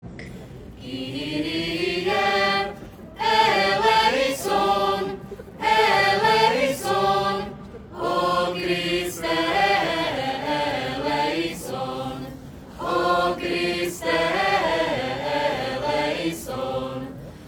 Brno - Pedagogická fakulta Masarykovy univerzity (PED MUNI) slavnostně rozsvítila vánoční strom.
Mezitím se však povedlo rozsvítit stromek, návštěvníci si poté zapálili prskavky a následně se i se sborem mohli pustit do koled.
Nálada panovala i přes drobné karamboly výborná, všude byl slyšet šum rozhovorů, u stánku se svařákem a moštem se chvílemi tvořila i fronta.